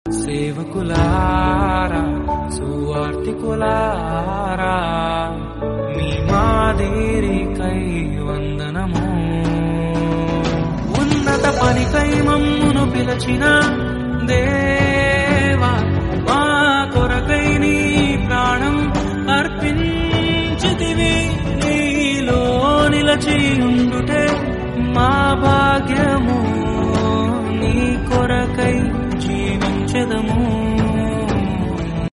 devotional ringtone